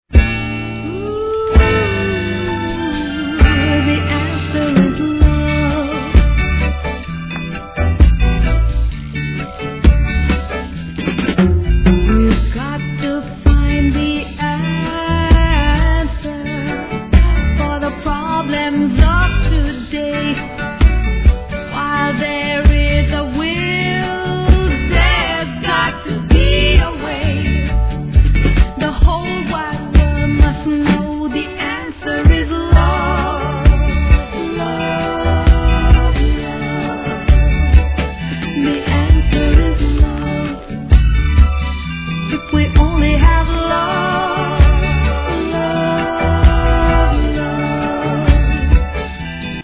REGGAE
'70sフィメール･ヴォーカル